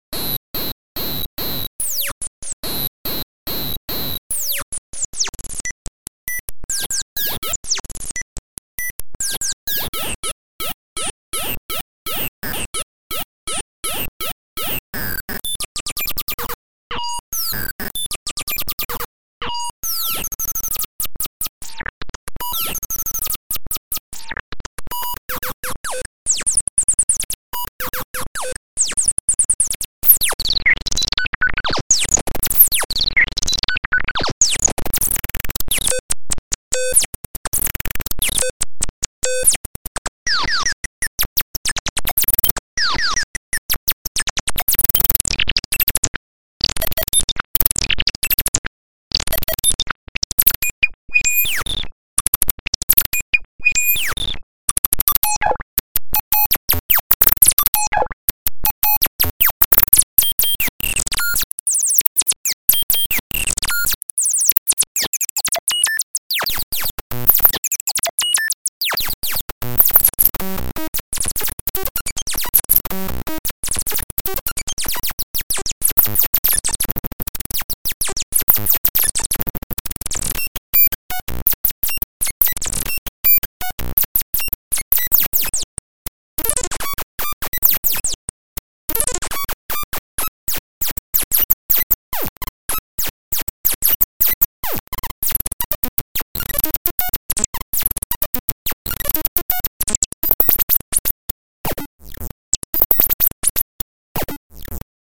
All birds sing in mono.